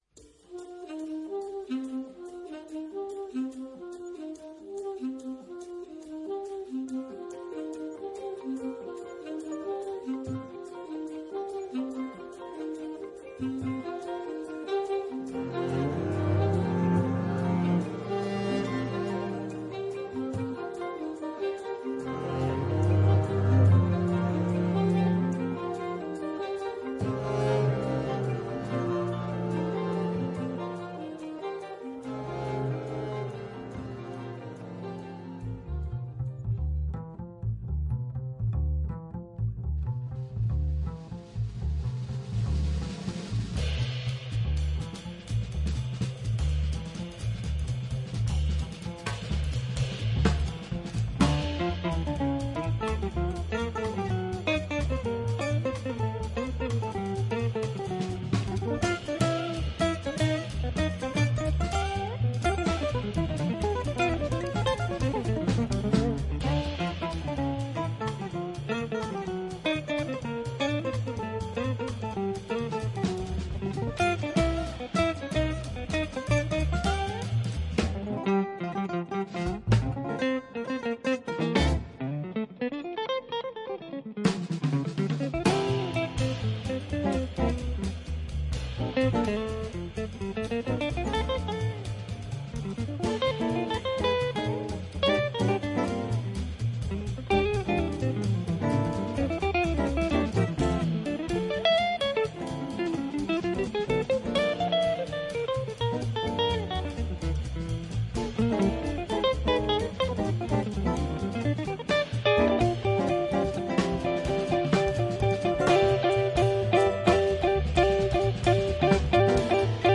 Centraal staat de promotie van jazz en beyond. Te gast is deze keer zangeres